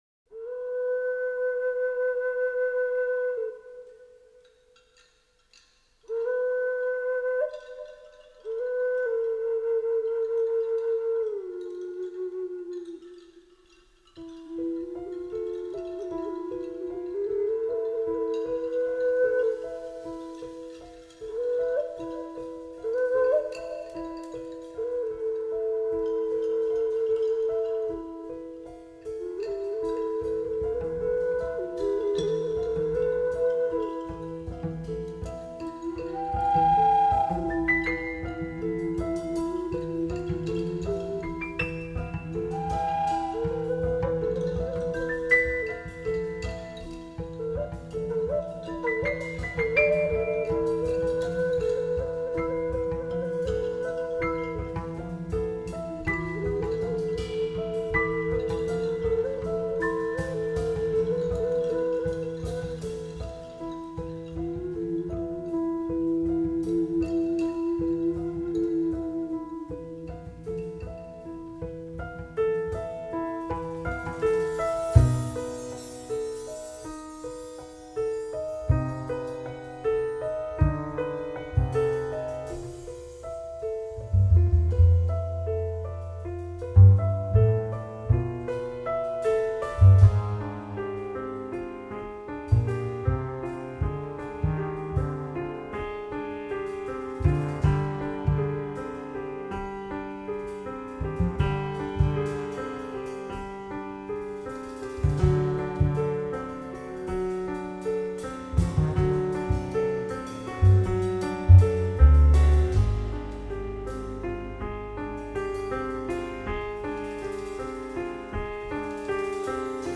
sassofoni e flauti
Chitarre
pianoforte